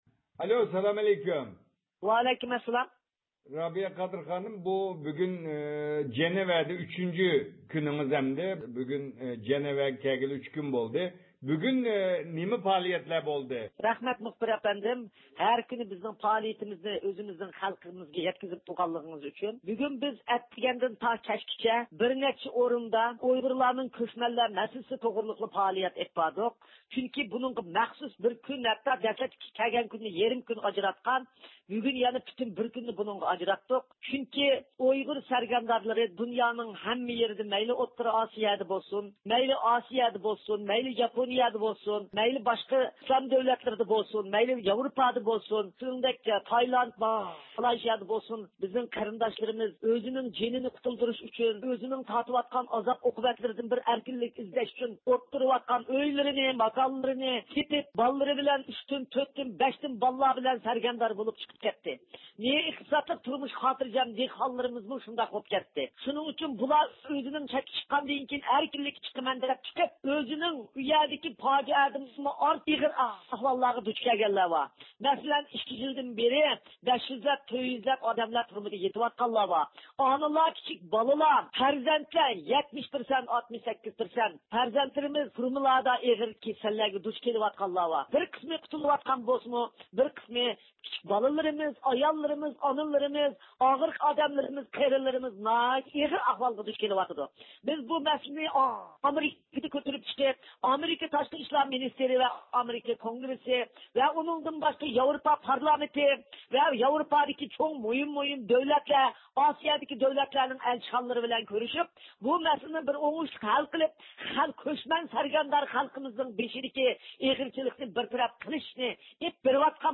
رابىيە قادىر خانىمنىڭ ئېيتىشىچە، ب د ت مۇساپىرلار باشقارمىسىنىڭ مەسئۇللىرى، ئۇيغۇرلارنىڭ بۈگۈنكى ۋەزىيىتىدىن خەۋەردار ئىكەنلىكىنى، مۇساپىر ئۇيغۇرلار مەسىلىسىنى ھەل قىلىش ئۈچۈن قولىدىن كەلگەننى قىلىدىغانلىقىنى دېگەن. بۇ ھەيئەتنىڭ ئۇيغۇر مۇساپىرلار توغرىسىدا ئېلىپ بارغان ئۇچرىشىشلىرى توغرىسىدا تېخىمۇ تەپسىلى مەلۇمات ئىگىلەش ئۈچۈن رابىيە قادىر خانىم بىلەن سۆھبەت ئېلىپ باردۇق.